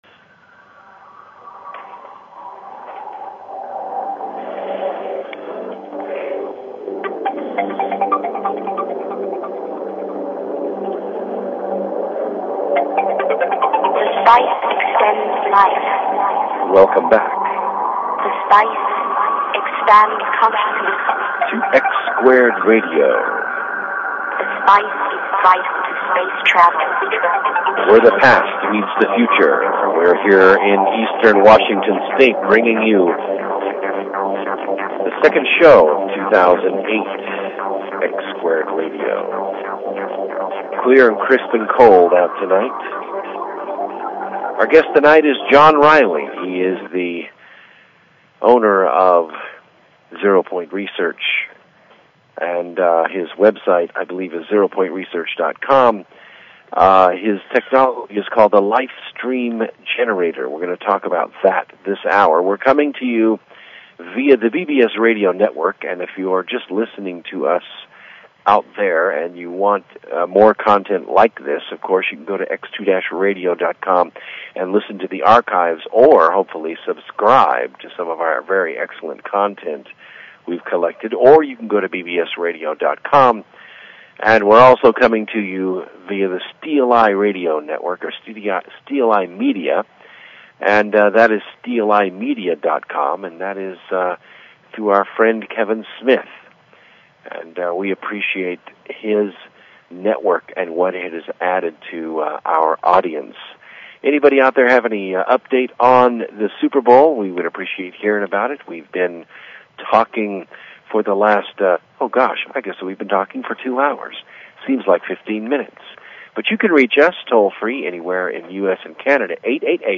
The X2 Radio Interview on Feb 3